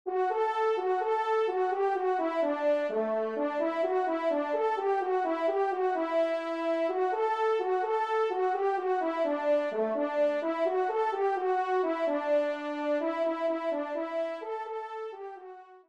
Trompe Solo (TS)